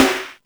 Index of /musicradar/essential-drumkit-samples/80s Digital Kit
80s Digital Snare 03.wav